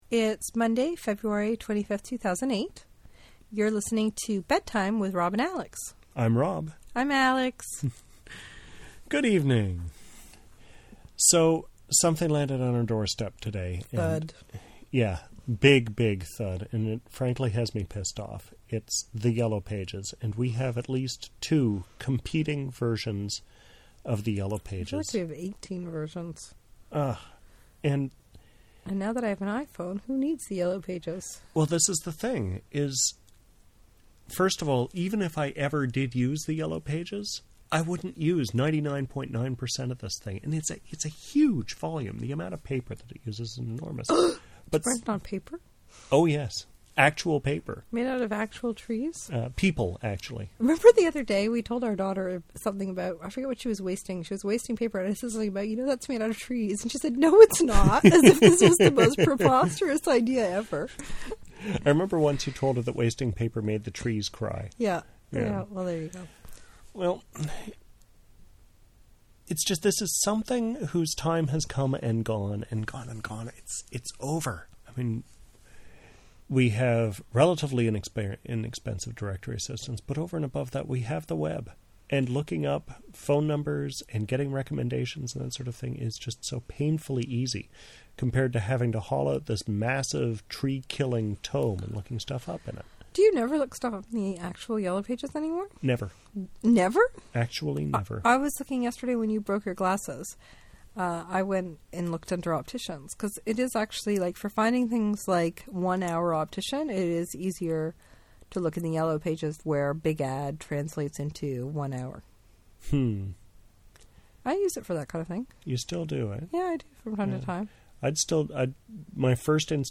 Here's hoping you'll find it easier on your ears.